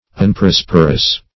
unprosperous - definition of unprosperous - synonyms, pronunciation, spelling from Free Dictionary
unprosperous.mp3